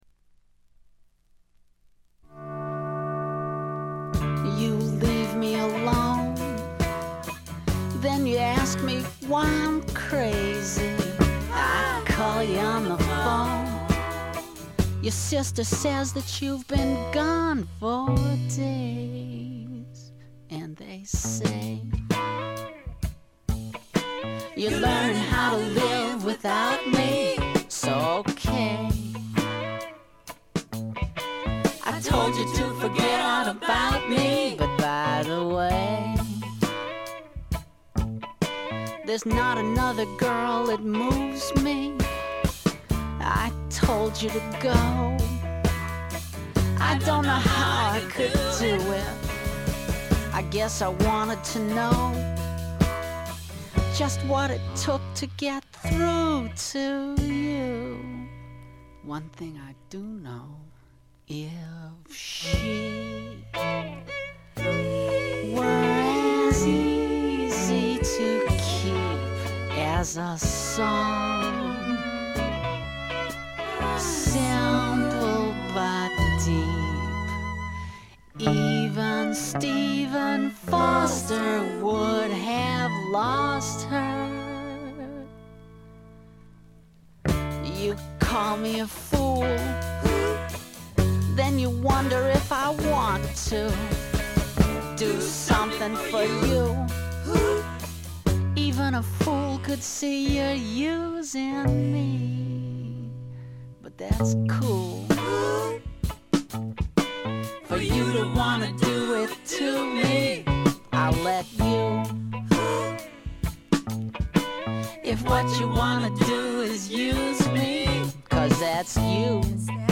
気付いたのはこれぐらい、ほとんどノイズ感無し。
試聴曲は現品からの取り込み音源です。